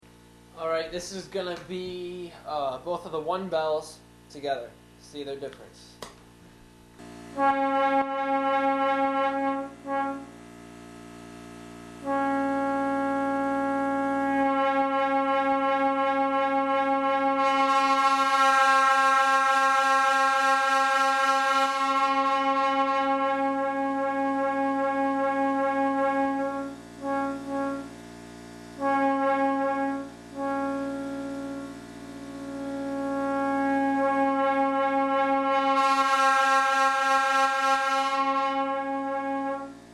Comparison of both 1 bells at the same time.
P5A_TESTS_1Bells.mp3